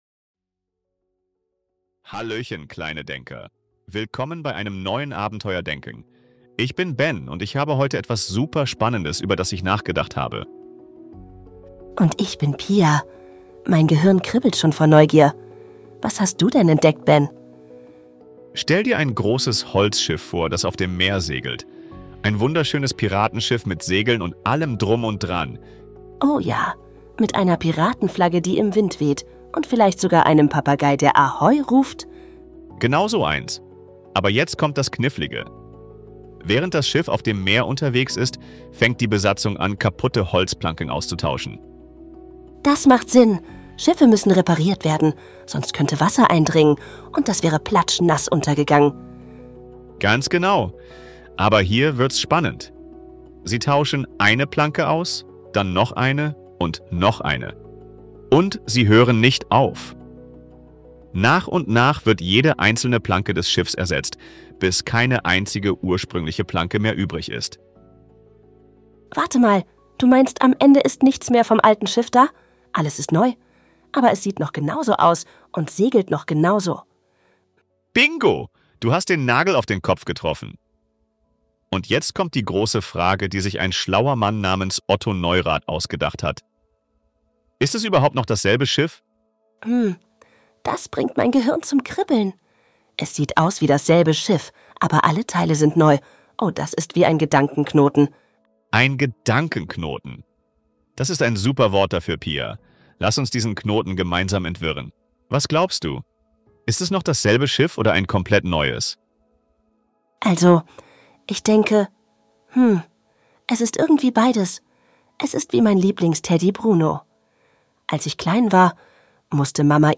Stell dir vor: Während das Schiff segelt, wird jede einzelne Planke ausgetauscht - ist es dann noch dasselbe Schiff? In diesem lustigen Gespräch entdeckst du das berühmte "Schiff des Theseus"-...